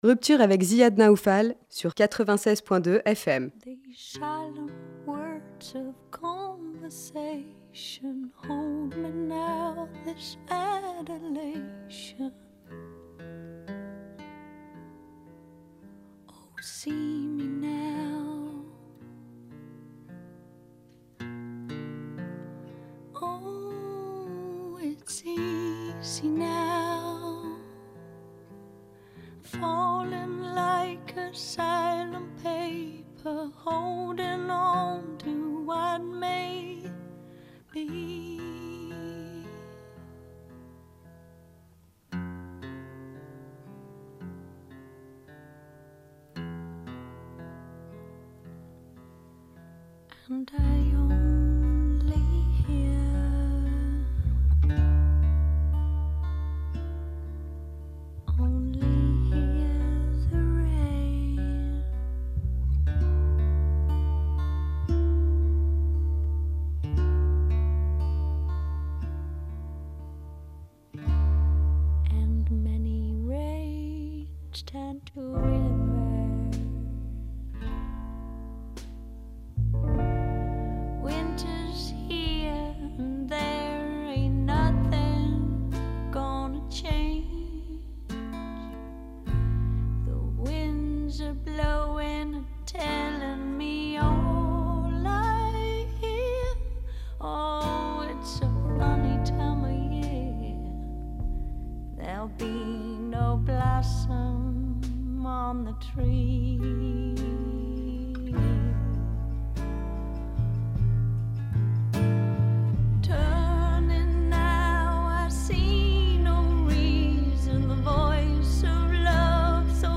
Lebanese Filmmaker Ghassan Salhab was the guest of the Ruptures radio program back in March, for an interview revolving around the making and release of his recent long feature film “The Valley”. He played a selection of his favorite tracks, and excerpts from his own films’ soundtracks as well.